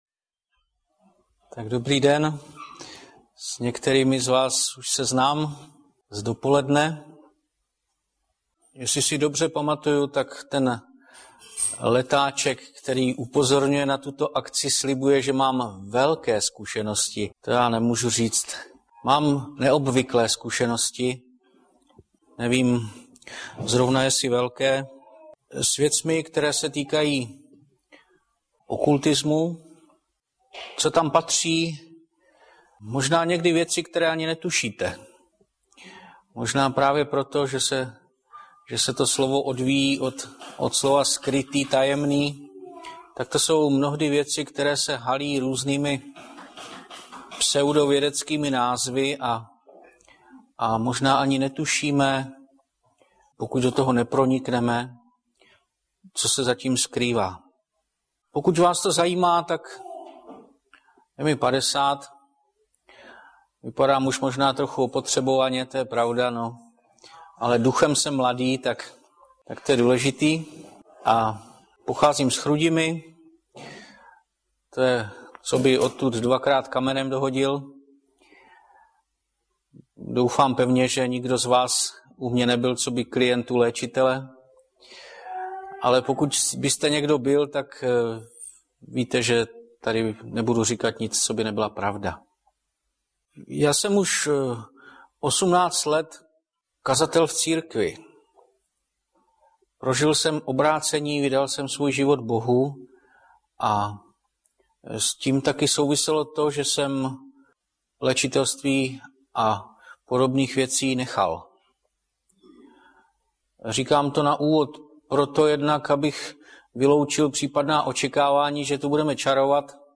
Webové stránky Sboru Bratrské jednoty v Litoměřicích.